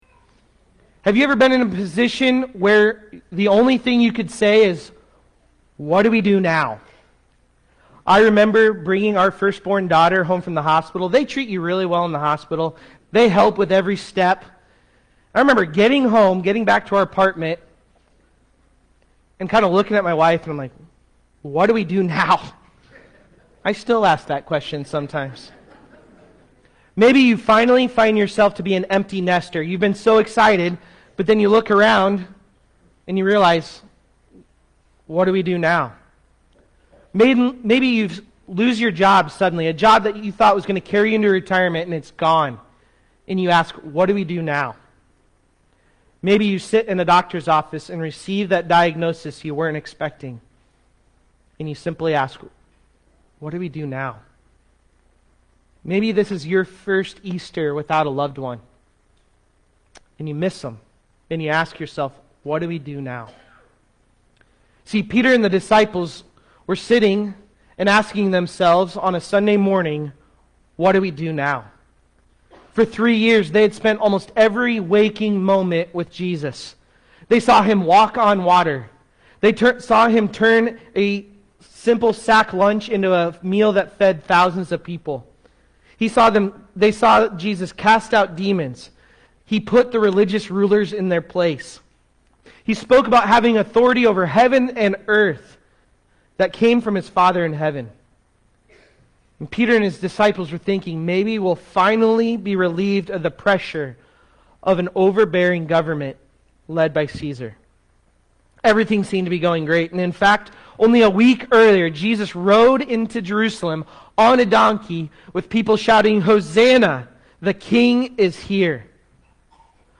This-Changes-Everything-Sermon-Audtio.mp3